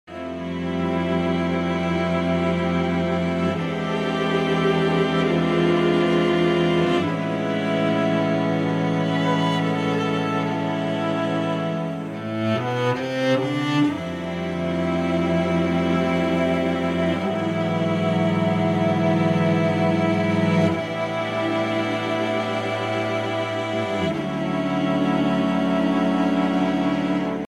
majestic intro